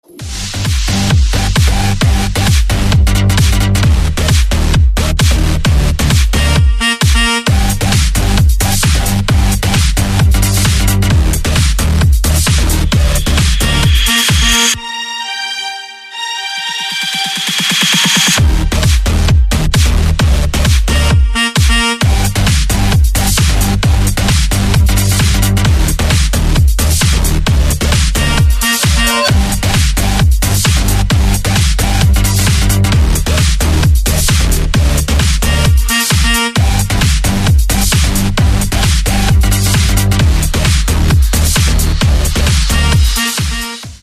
ритмичные
громкие
dance
Electronic
электронная музыка
без слов
future house
club